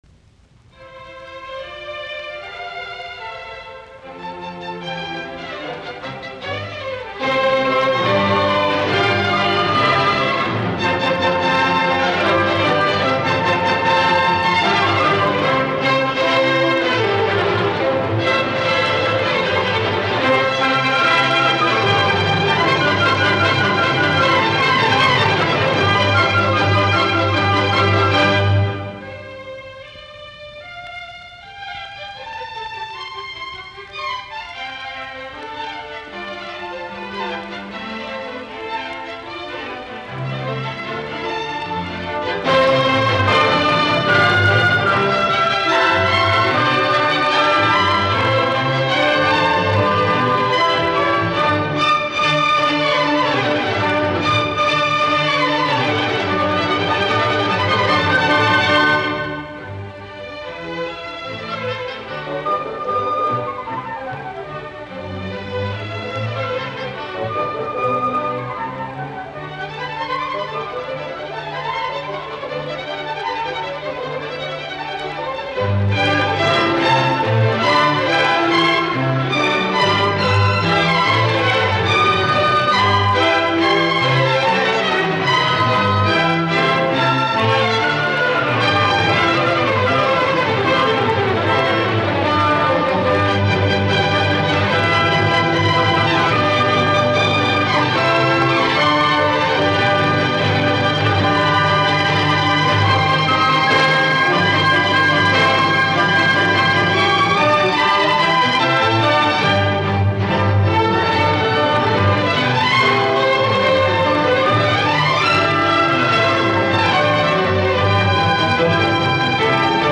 C-duuri
Andante cantabile